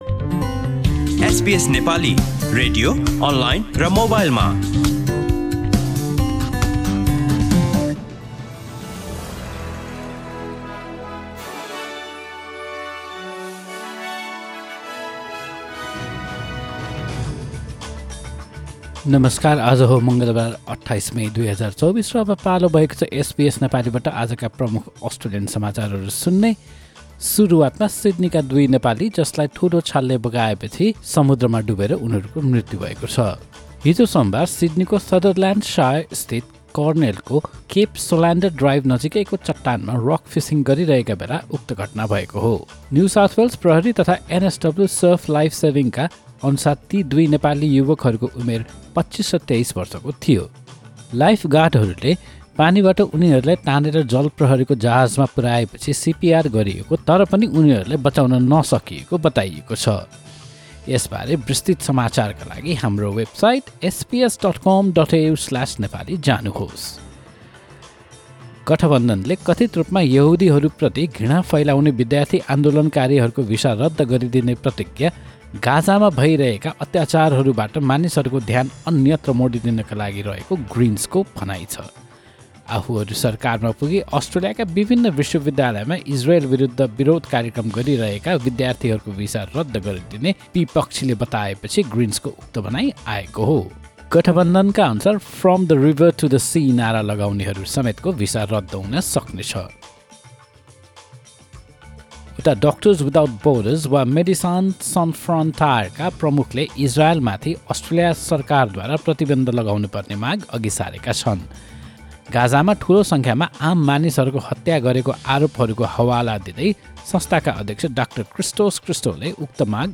SBS Nepali Australian News Headlines: Tuesday, 28 May 2024